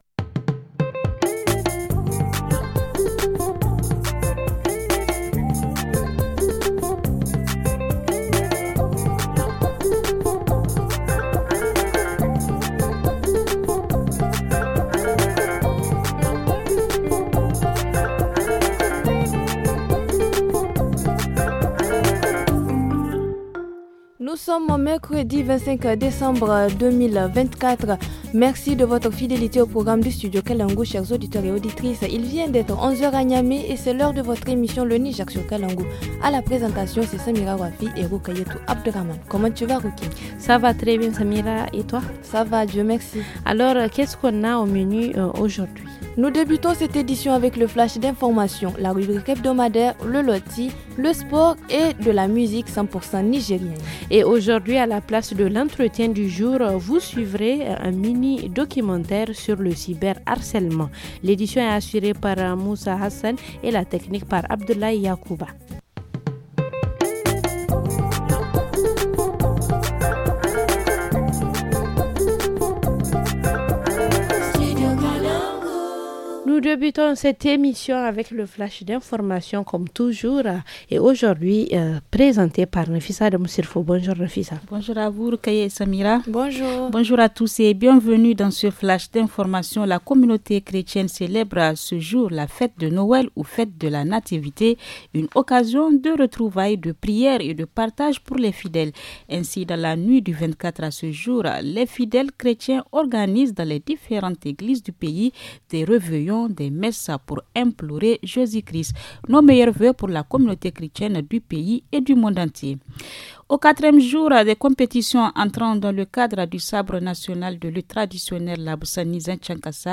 Dans l’émission du 25 décembre : entretien du jour, vous suivrez un mini documentaire sur le cyber harcèlement. En reportage région, lutte contre les violences faites aux enfants à Diffa.